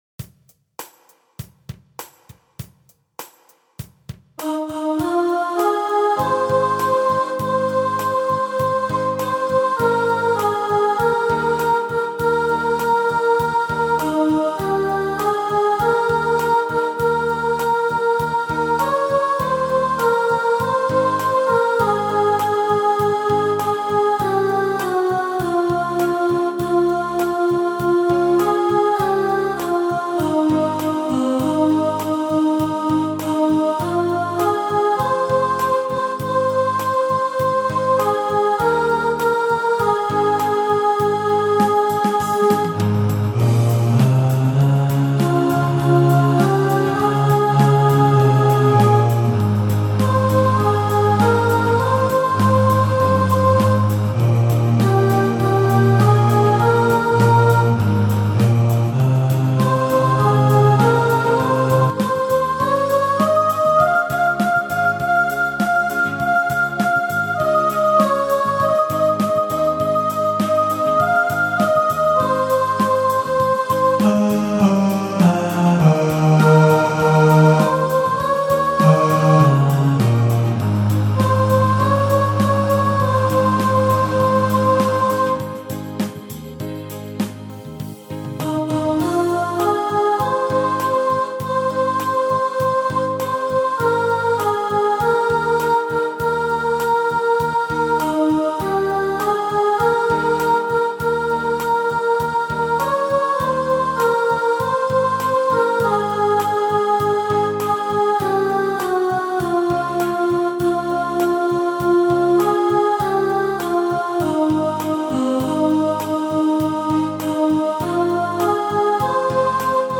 Himno basado en el Salmo 27